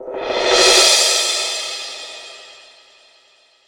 • Crash Sound Sample D Key 03.wav
Royality free crash one shot tuned to the D note.
crash-sound-sample-d-key-03-8bD.wav